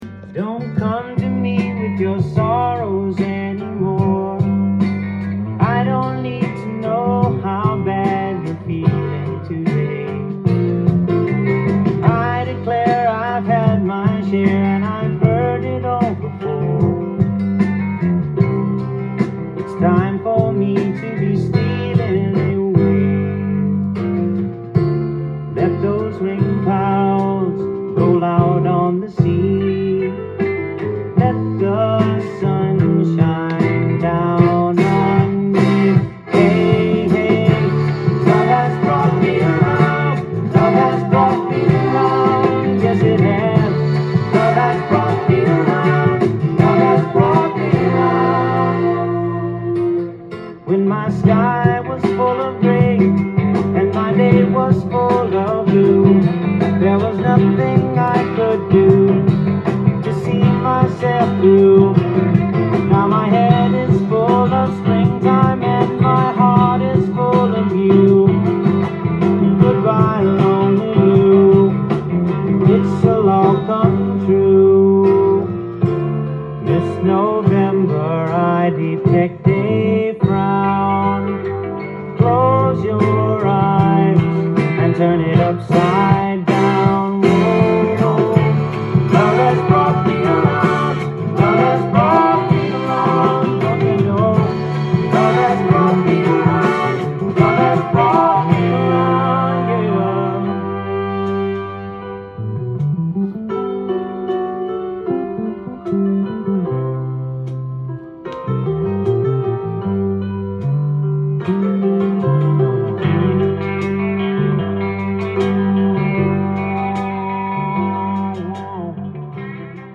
LP
店頭で録音した音源の為、多少の外部音や音質の悪さはございますが、サンプルとしてご視聴ください。